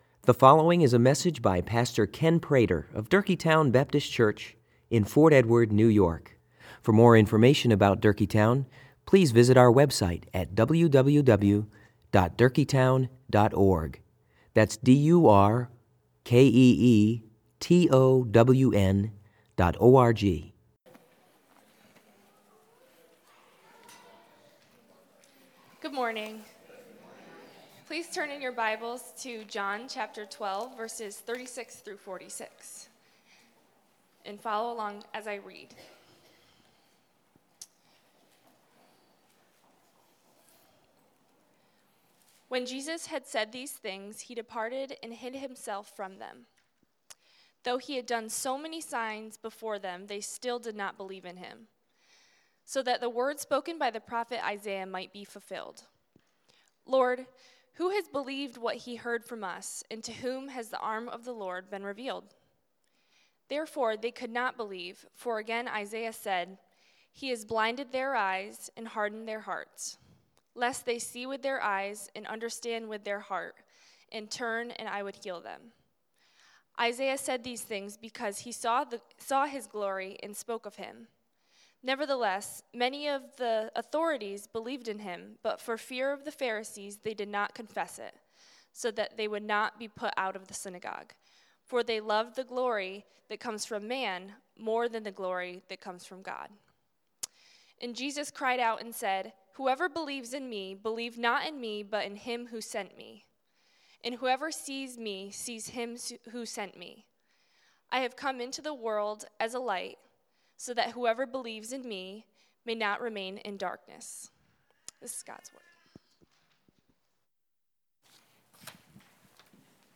Sermons
Sermons from Durkeetown Baptist Church: Fort Edward, NY